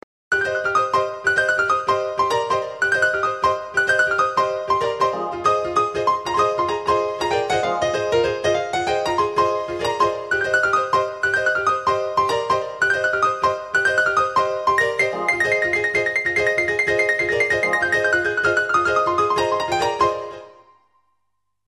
but they just sound like standard phone ringtones to me